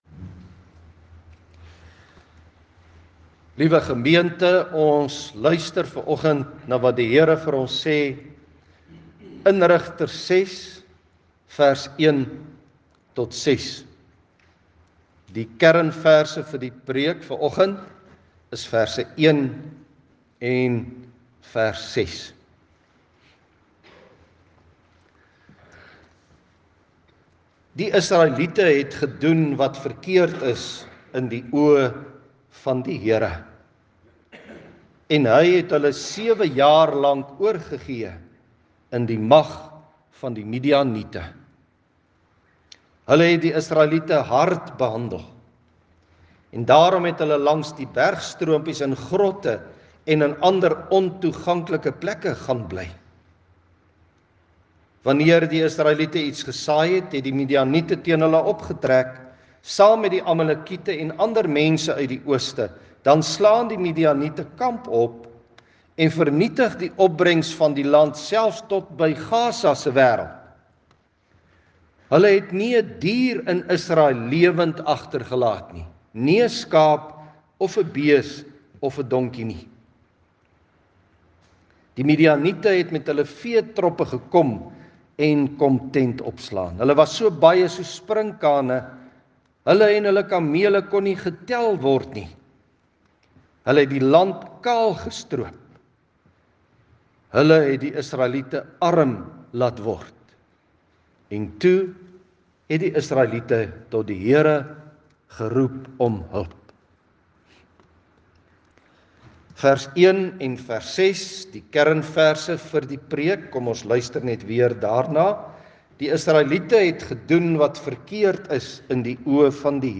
Seën: Genade, barmhartigheid en vrede van God ons Vader en Jesus Christus ons Here deur die werk van die Heilige Gees Lees: Rigters 6:1-6 Inleiding: Vanoggend begin ons ‘n nuwe reeks uit Rigters 6 tot 8. Hierdie eerste preek gaan oor sonde en die slegte gevolge, die ellende wat sonde veroorsaak, terugkeer na die Here en die uitkoms wat net by Hom is.